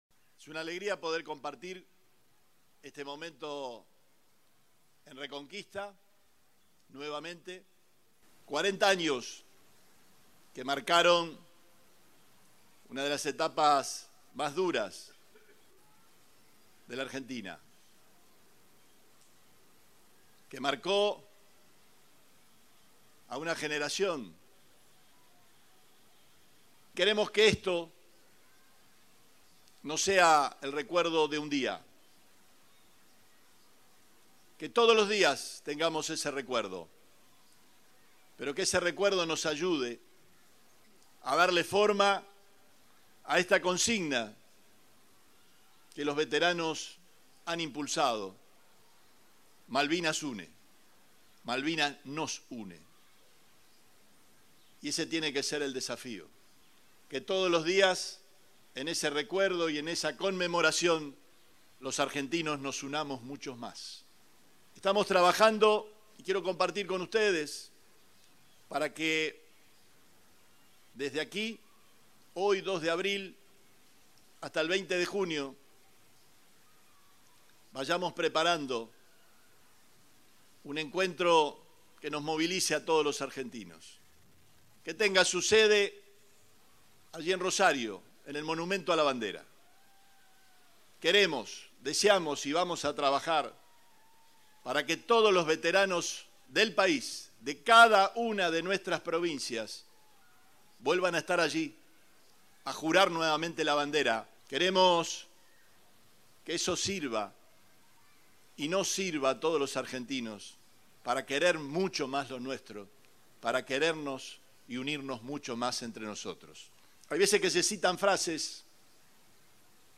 Perotti encabezó el acto por el 40° aniversario de la Guerra de Malvinas - Día del Veterano y de los Caídos en Malvinas, que se realizó este sábado en la ciudad de Reconquista.